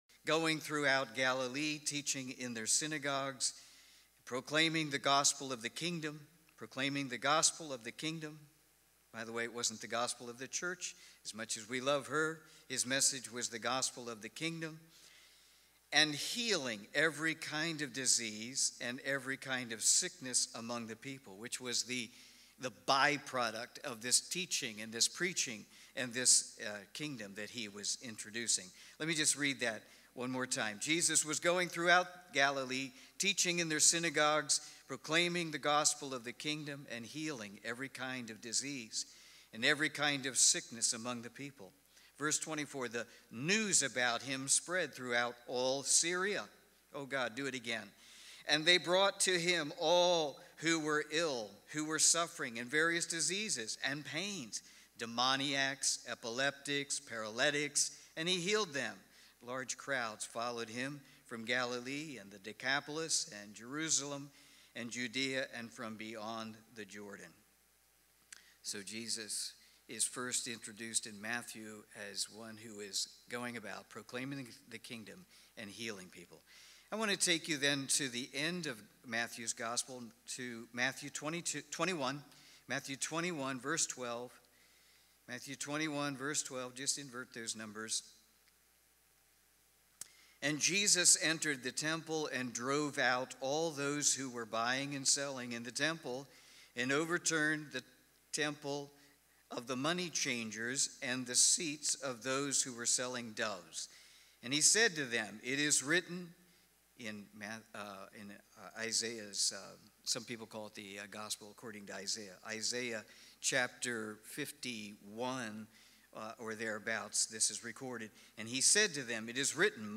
Sunday morning service, recorded live at the church, Sept. 6, 2020. Heartfelt worship